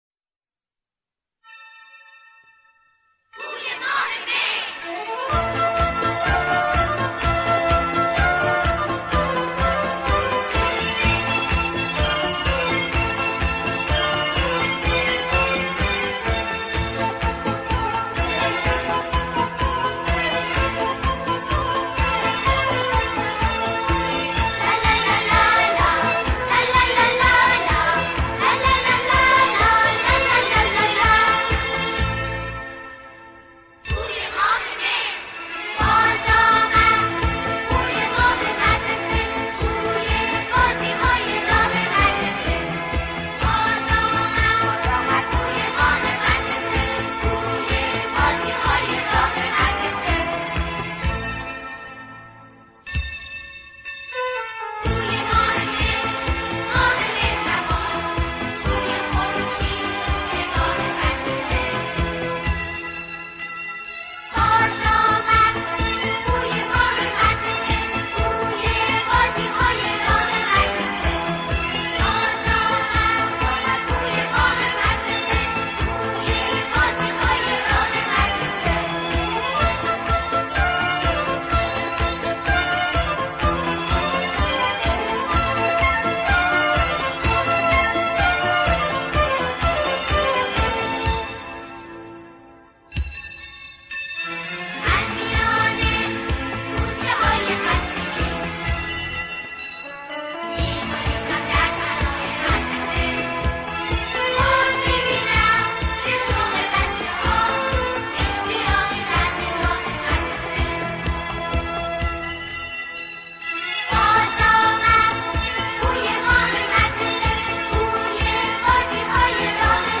سرودهای مدرسه